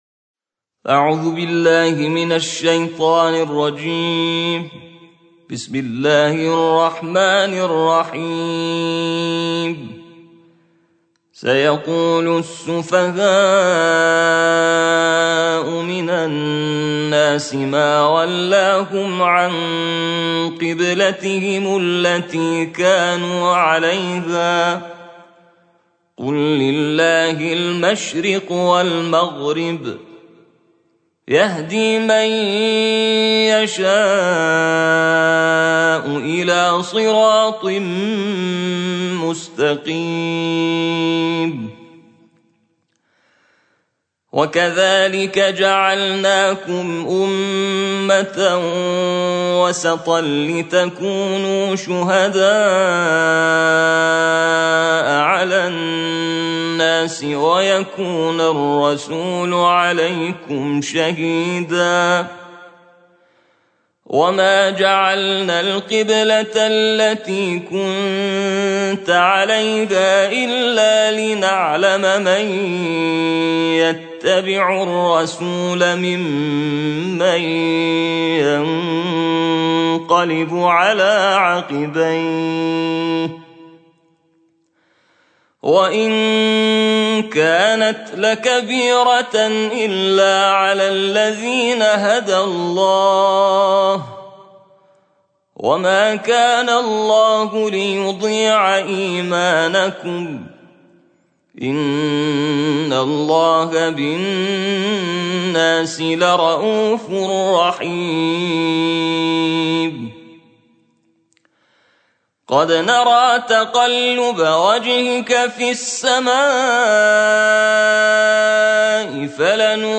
ترتیل جزء دوم قرآن کریم/دعا چگونه مستجاب می‌شود؟+صوت و متن آیات